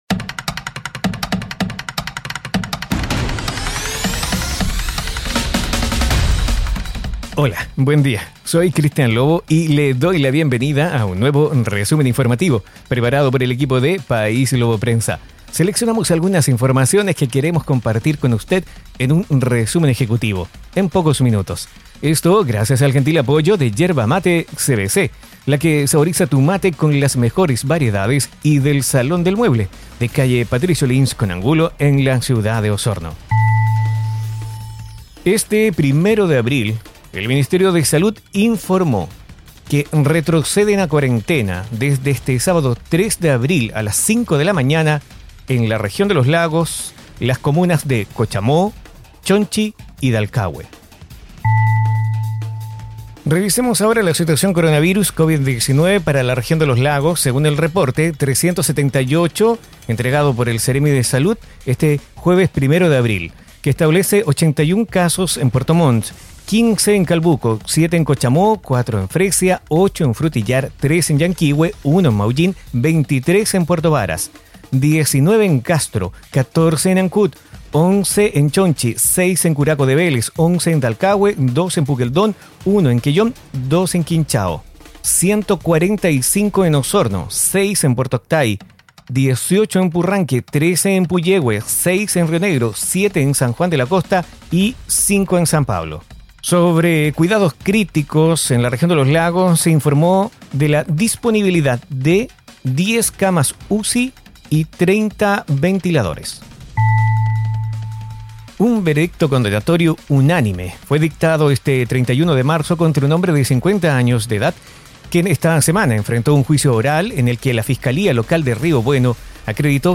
Resumen Informativo: 01 de abril de 2021
Informaciones enfocadas en la Región de Los Lagos. Difundido en radios asociadas.